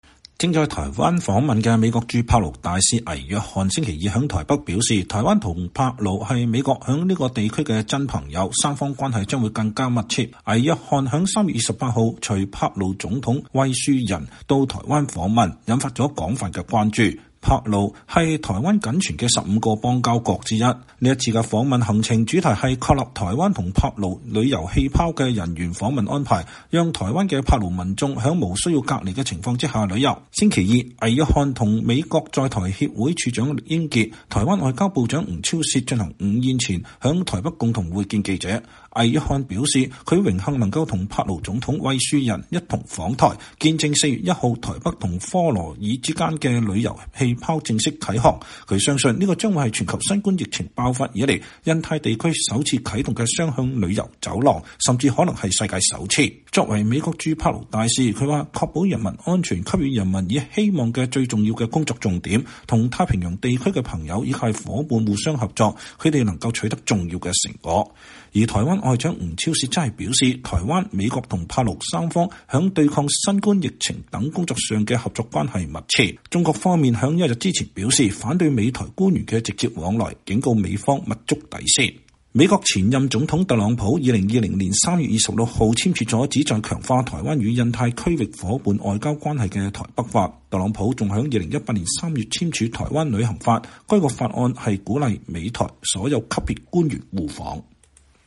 美國駐帕勞大使倪約翰（John Hennessey-Niland）2021年3月30日在台北致辭